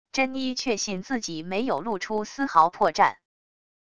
珍妮确信自己没有露出丝毫破绽wav音频生成系统WAV Audio Player